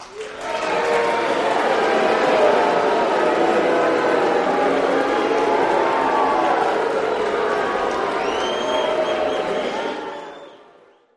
Crowd Boo, Yeah, Left and Right, Intro Sensei